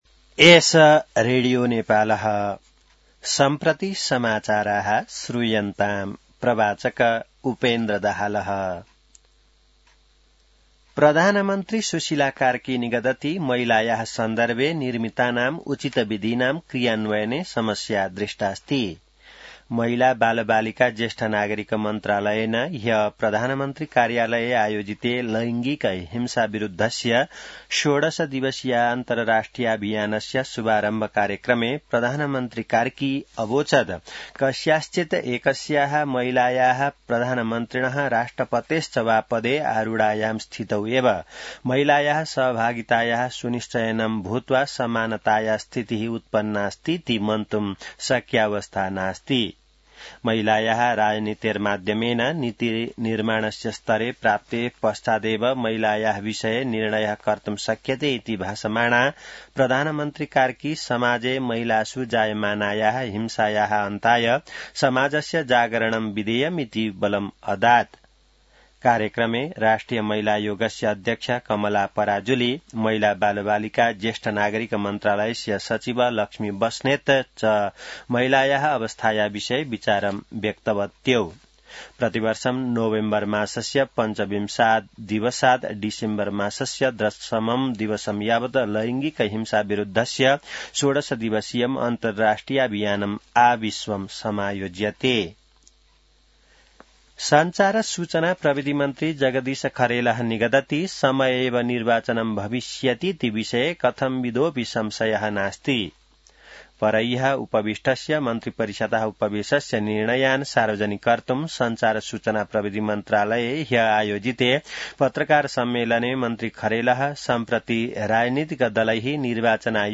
संस्कृत समाचार : १० मंसिर , २०८२